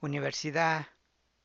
unibersida[unibersidaa]